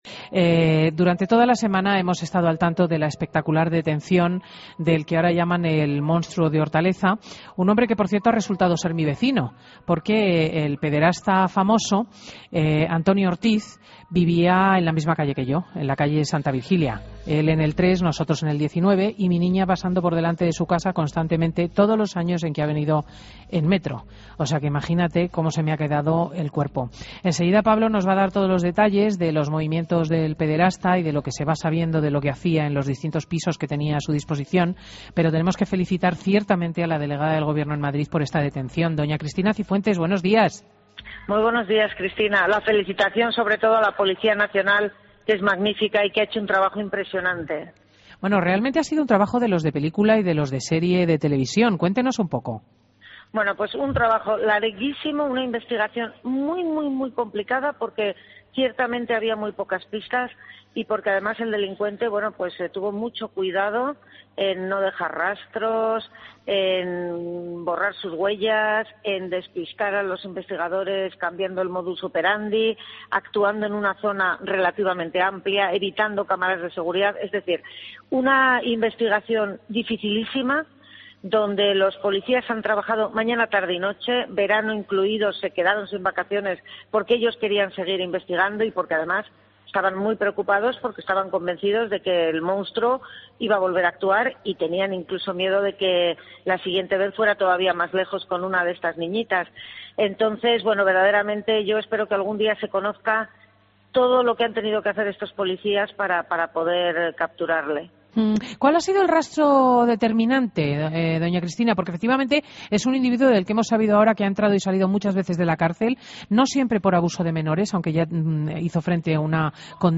AUDIO: Entrevista a Cristina Cifuentes en Fin de Semana COPE